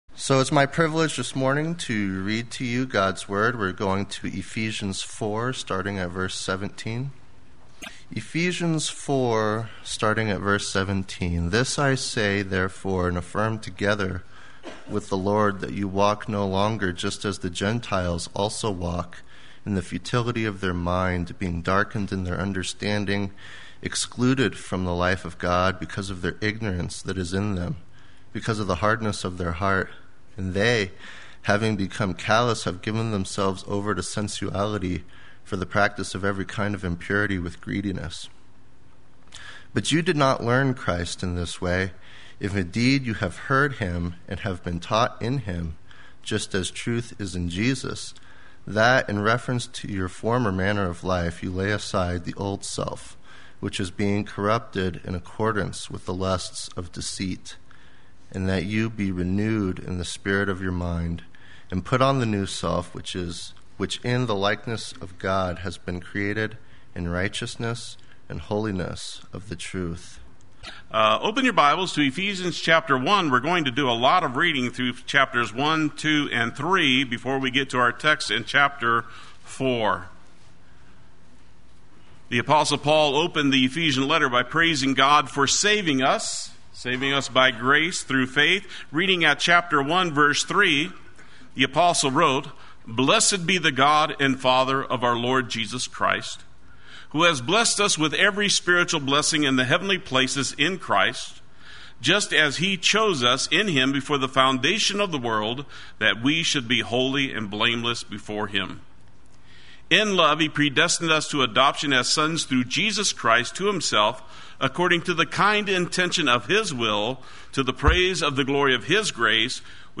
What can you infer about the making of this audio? “Put on the New Self” Sunday Worship